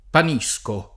panisco
vai all'elenco alfabetico delle voci ingrandisci il carattere 100% rimpicciolisci il carattere stampa invia tramite posta elettronica codividi su Facebook panisco [ pan &S ko ] s. m. (mit.); pl. -schi — genietto dei boschi (dim. di Pan )